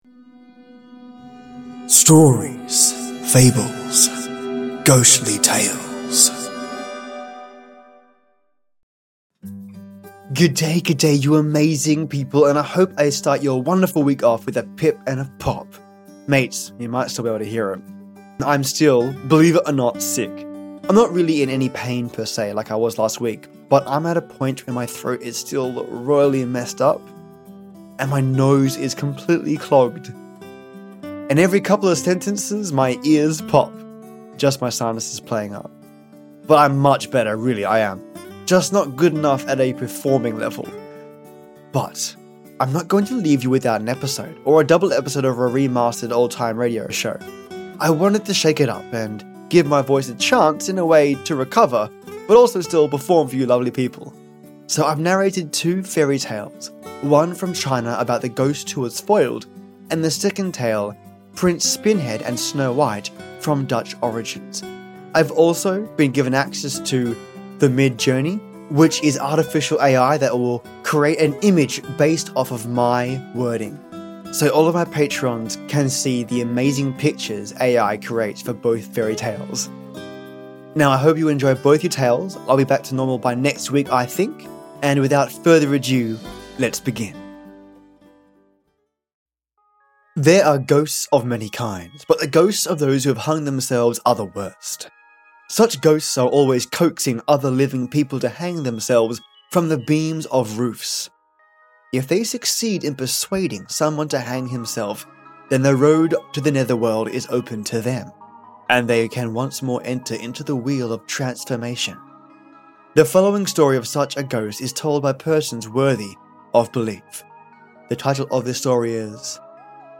Two Unique Fairy Tales, narrated by a "still sick" narrator 😁